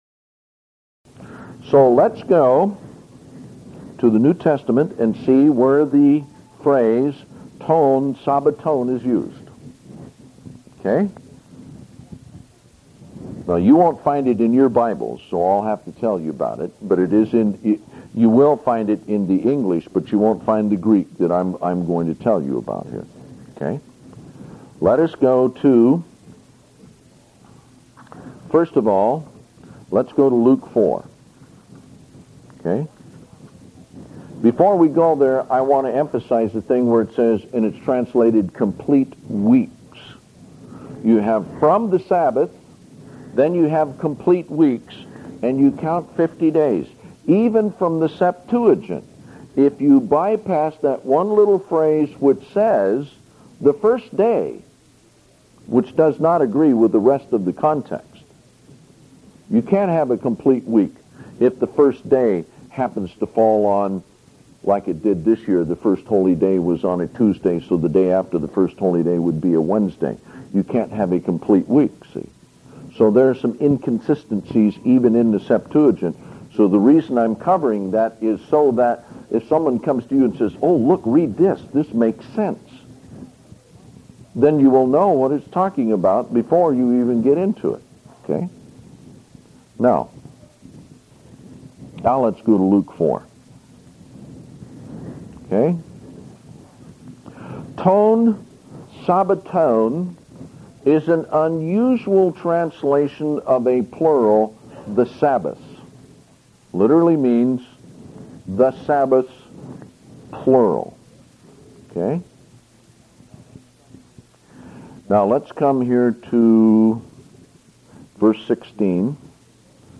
02CounttoPentecost-Sermon1Part2of2.mp3